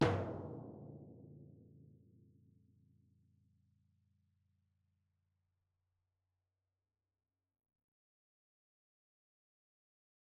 Timpani Small
Timpani1A_hit_v5_rr1_main.mp3